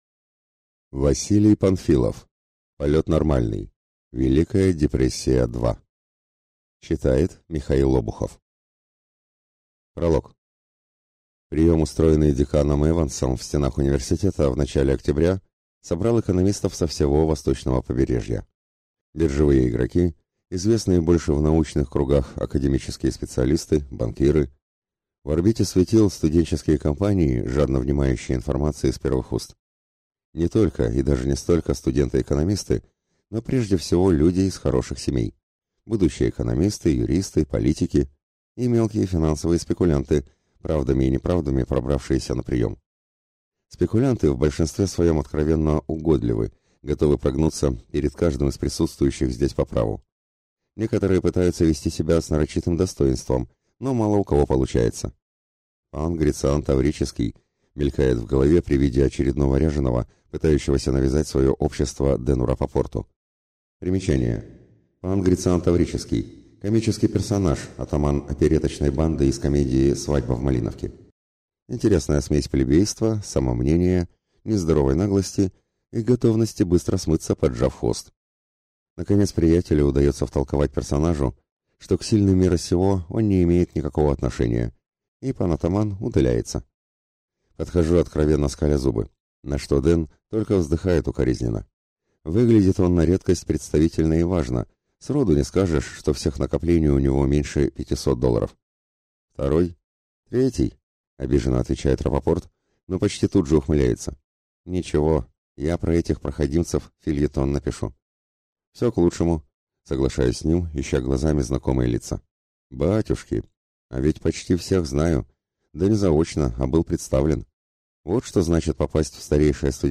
Aудиокнига Полет нормальный…